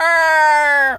bird_vulture_croak_04.wav